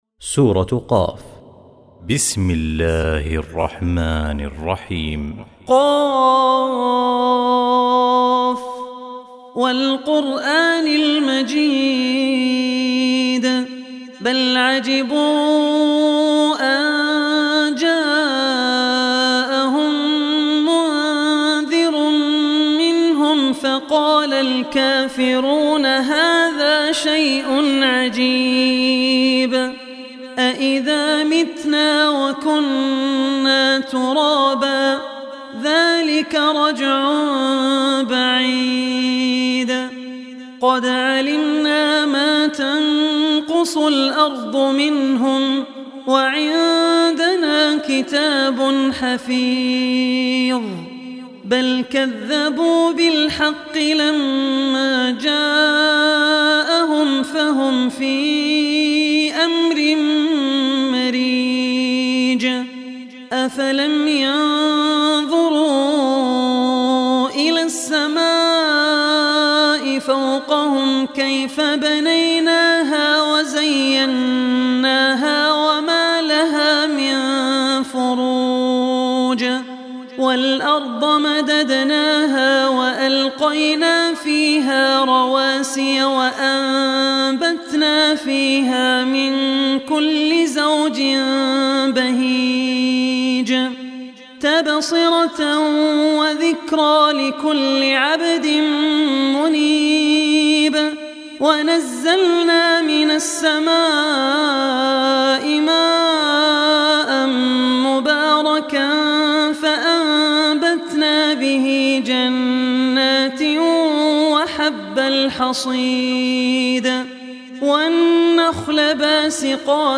Surah Qaf Recitation